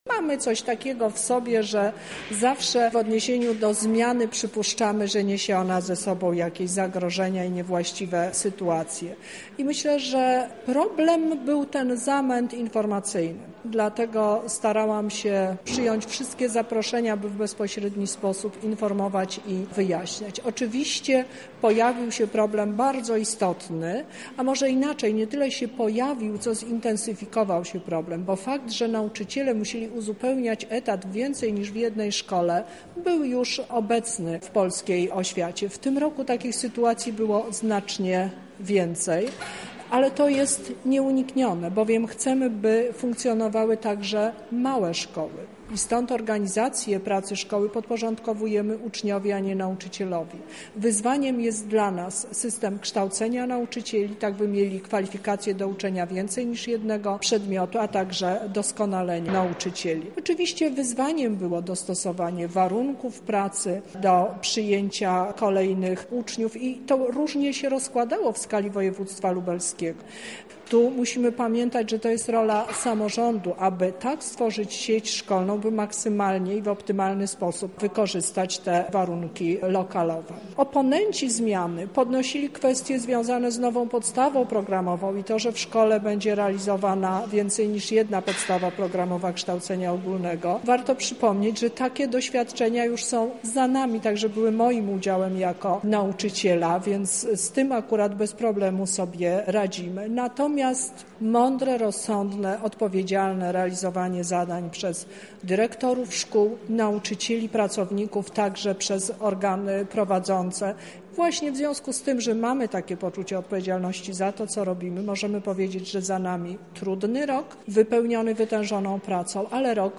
Przed samorządami postawiono więc niełatwe zadanie zreorganizowania szkół. Jak sobie z tym poradzono mówi Teresa Misiuk, Lubelski Kurator Oświaty.